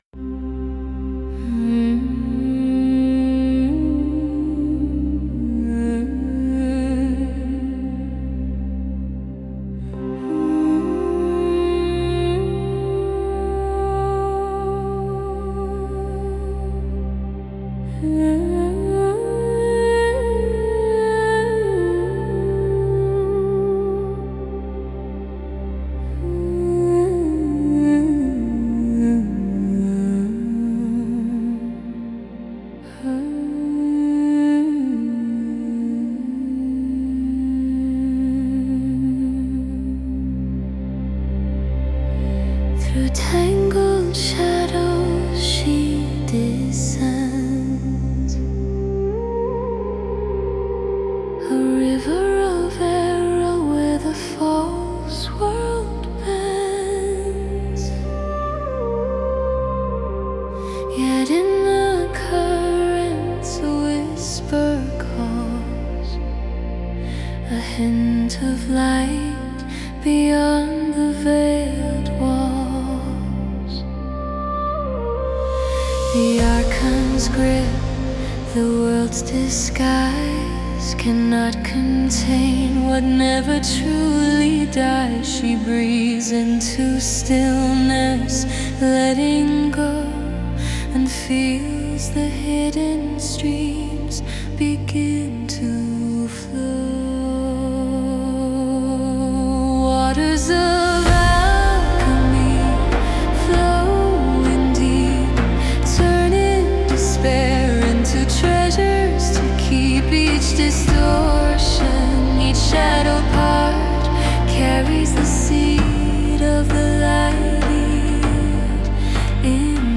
Full album with 8 Songs produced in 432Hz